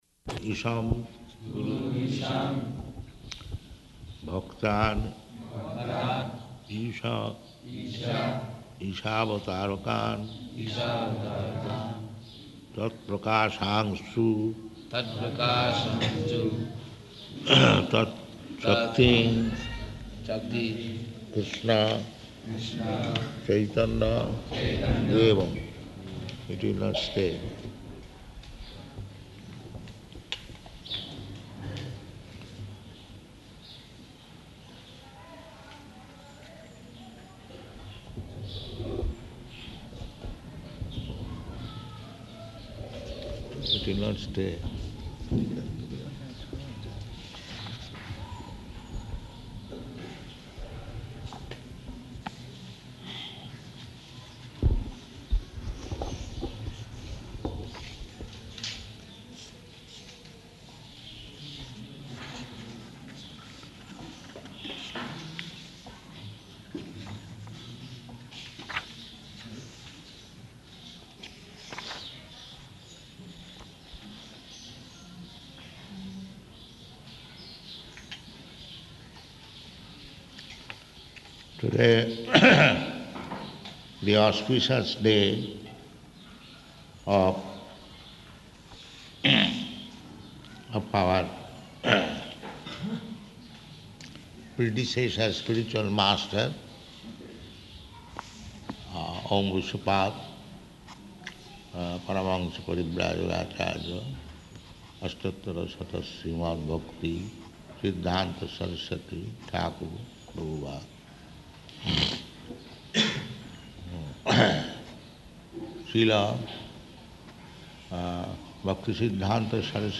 Śrīla Bhaktisiddhānta Sarasvatī Appearance Day Lecture
Śrīla Bhaktisiddhānta Sarasvatī Appearance Day Lecture --:-- --:-- Type: Appearance and Disappearance days Dated: February 21st 1976 Location: Māyāpur Audio file: 760221BA.MAY.mp3 Prabhupāda: [leads chanting of Cc. Ādi 1.1 responsively]